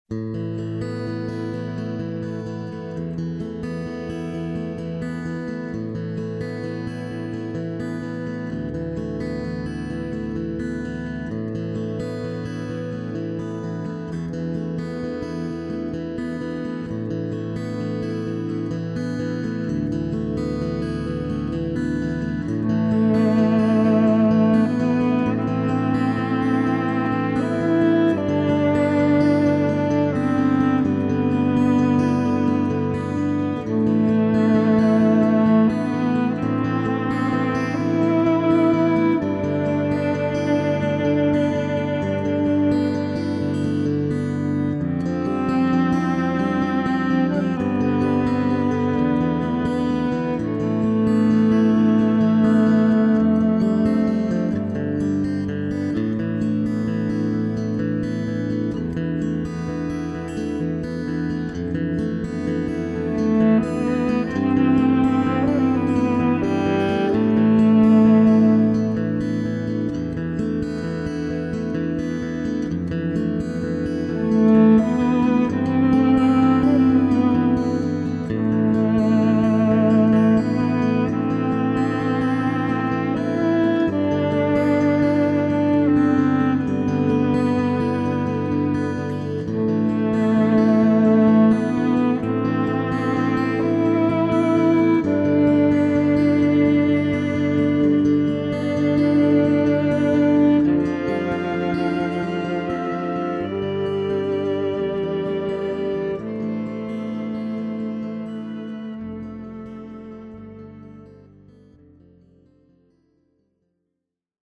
emotionally engaging celestial music